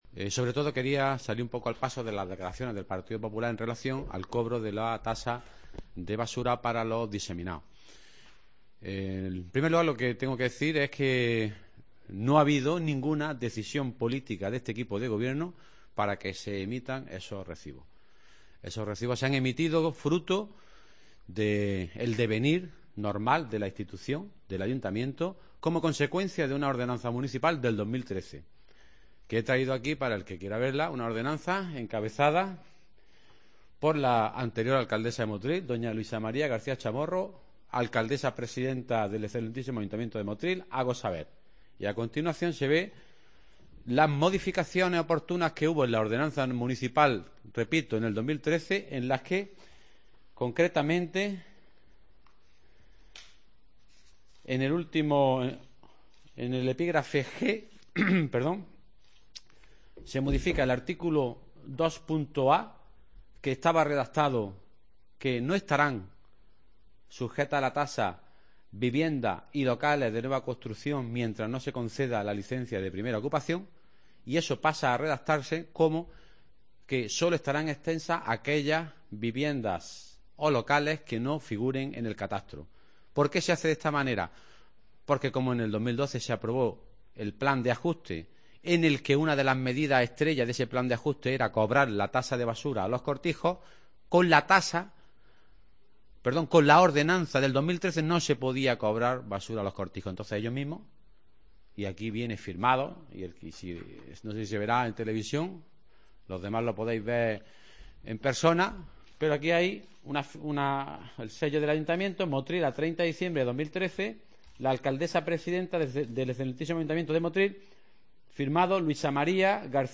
AUDIO: Rueda de prensa esta mañana sobre la basura de los cortijos, la relación de puestos de trabajo en el ayto. y la negativa del PP a aprobar...